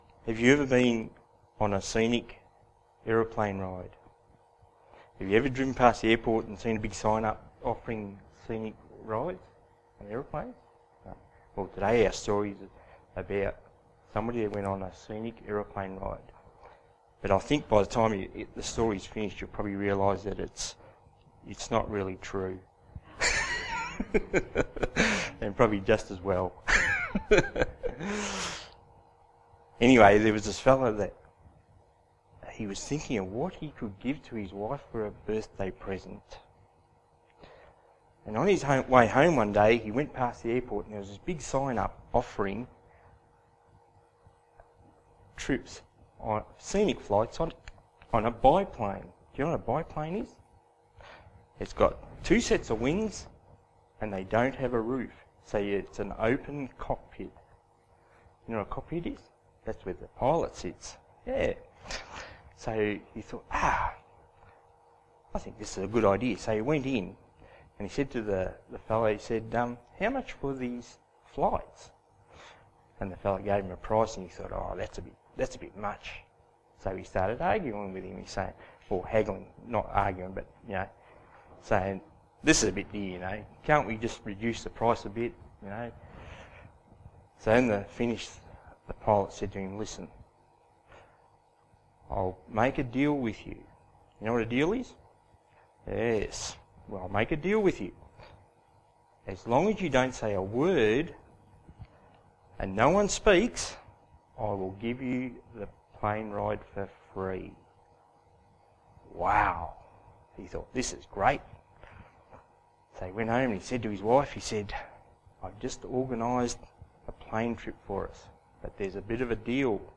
Children's Stories